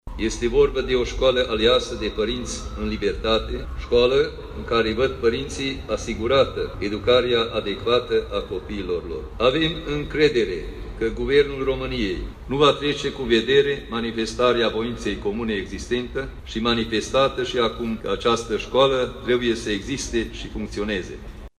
Peste 2.000 de persoane au protestat aseară la Tîrgu-Mureș.
Jakubinyi Gyorgy a făcut un apel către guvernanți să asigure funcționarea școlii maghiare de la Tîrgu-Mureș: